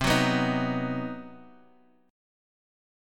CmM7b5 chord